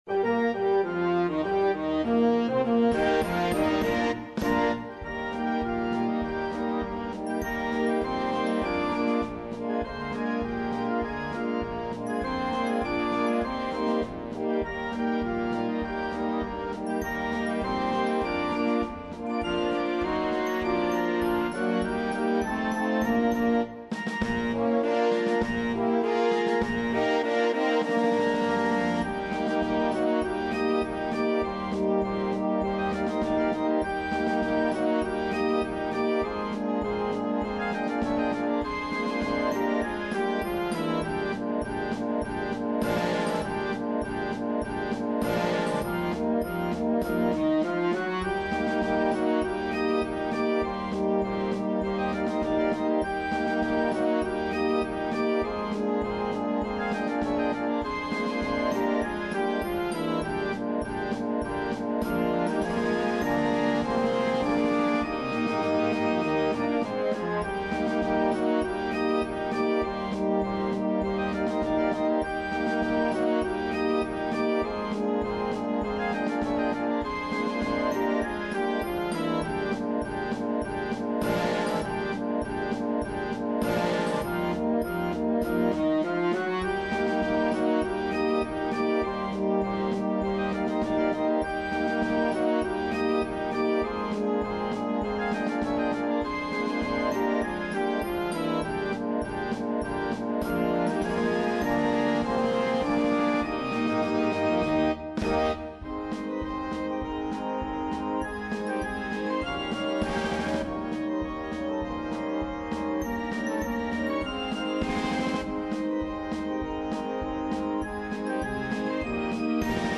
im Marschrhythmus geschriebenes Operetten-Lied